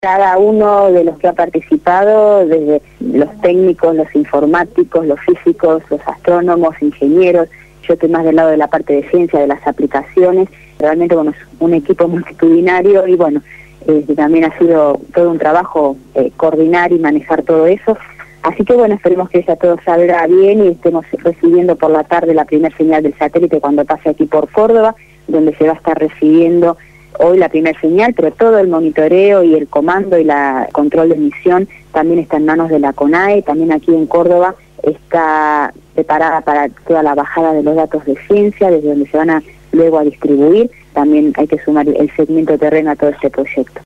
habló en Radio Gráfica FM 89.3 la mañana del viernes, antes del lanzamiento del satélite